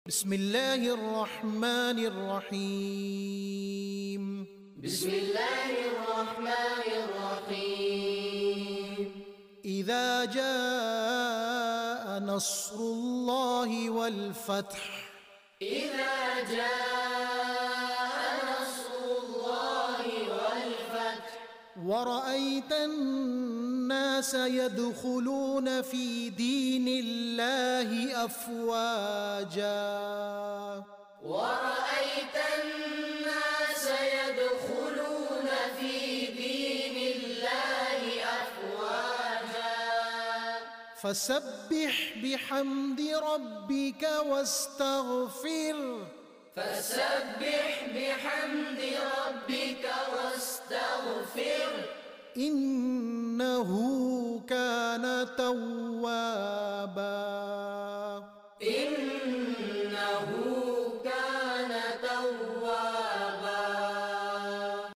surah an-Nasr When the help and conquest of Allah comes You will see people entering in Alla's Religion in multitudes So Glorify and worship Allah and seek for His forgiveness. wonderful Quranic recitation Kids reciting Quran